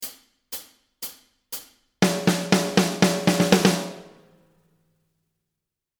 Etude pour Batterie